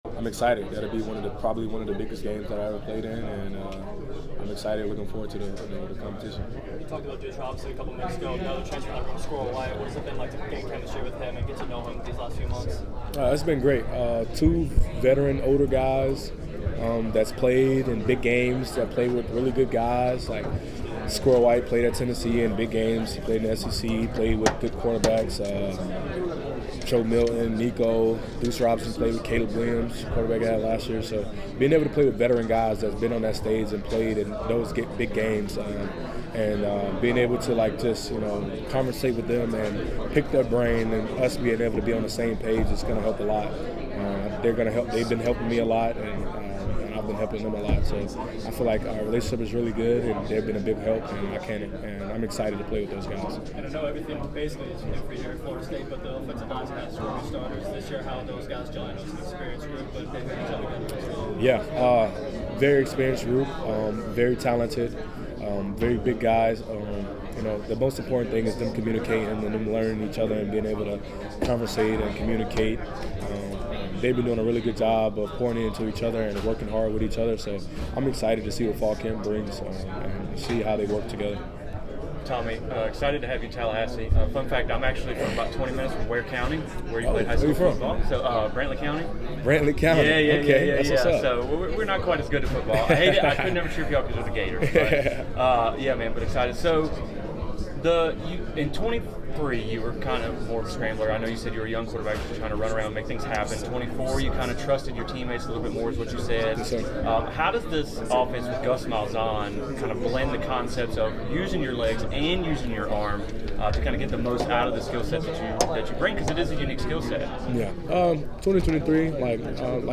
FSU QB Tommy Castellanos Breakout Interview at ACC Media Days
At ACC Football Kickoff 2025, I had the opportunity to have a brief conversation with FSU QB Tommy Castellanos. We discuss his fit in the Malzahn system, the weapons around him, a player to watch, and the difficulty of facing Tony White’s defense.